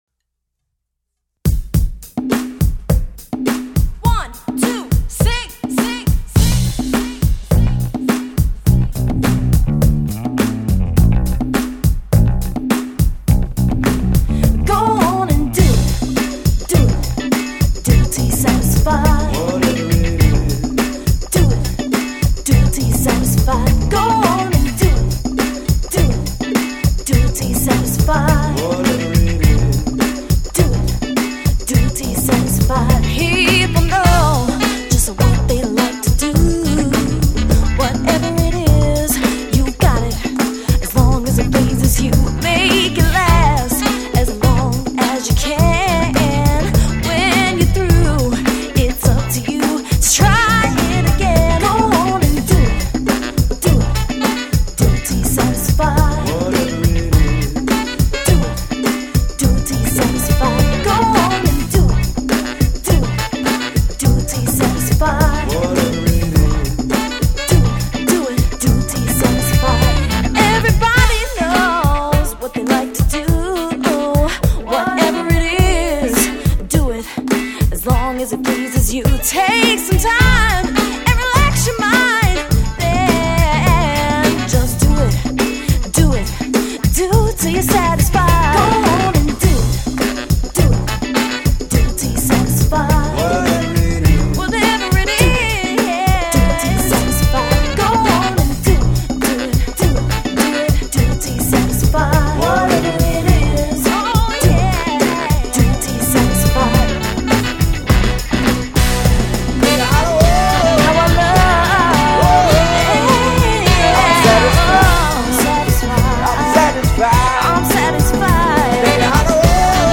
sung with passion and verve